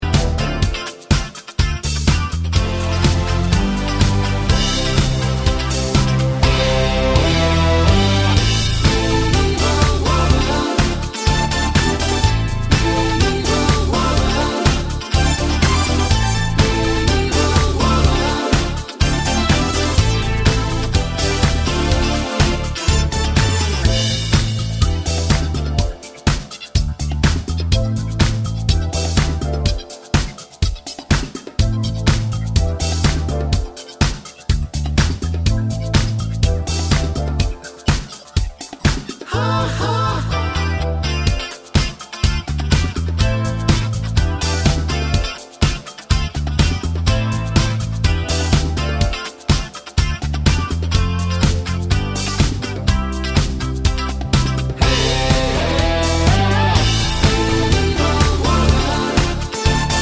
no Backing Vocals Pop (2020s) 3:50 Buy £1.50